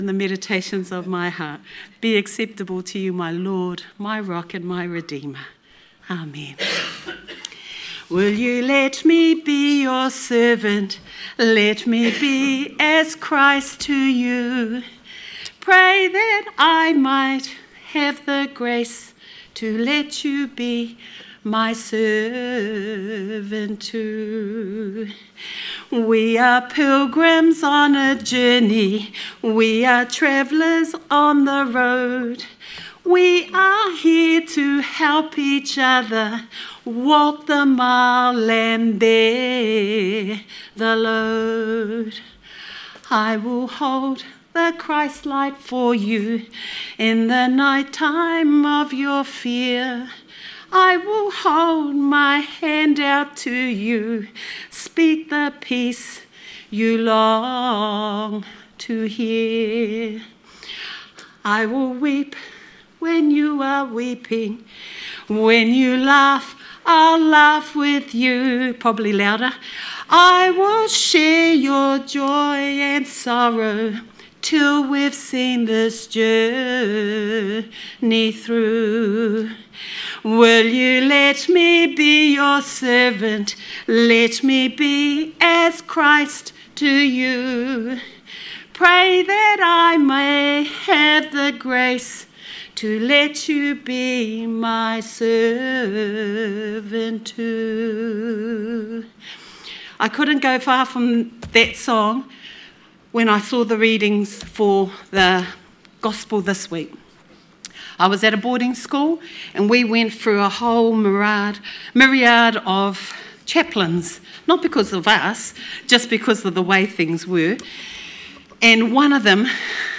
Sermon 5th October 2025